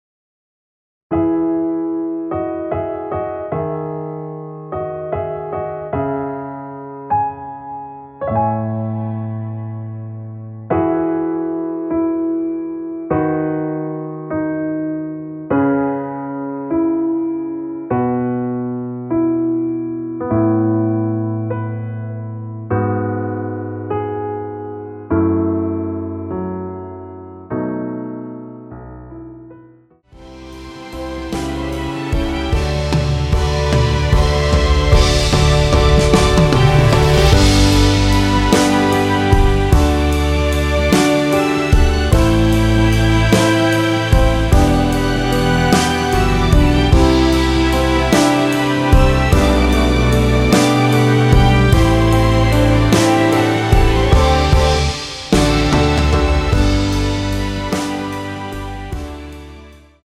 원키에서(-1)내린 MR입니다.
멜론에 있는 것보다 퀄은 괜찮은데 기타소리를 좀 더 신경 써 주시면 좋을 것 같아요.
앞부분30초, 뒷부분30초씩 편집해서 올려 드리고 있습니다.
중간에 음이 끈어지고 다시 나오는 이유는